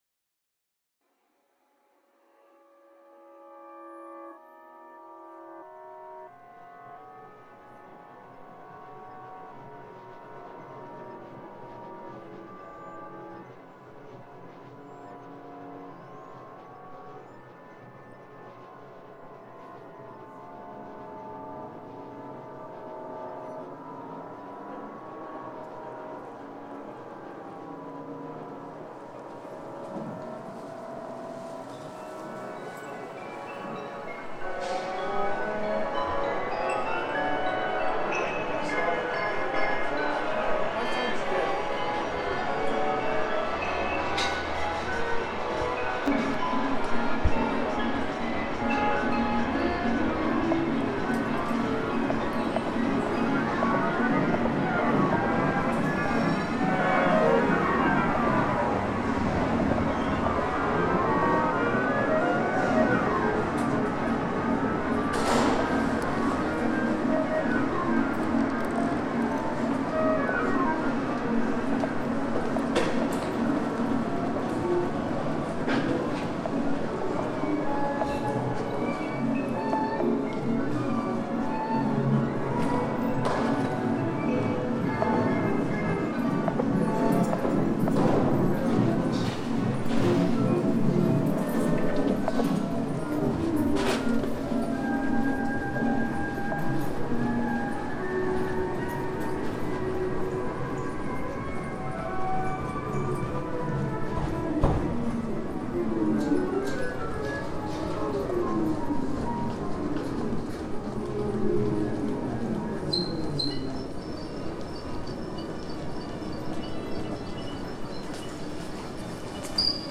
Antropoceno (2018) fixed media Program Notes Listen: Sorry.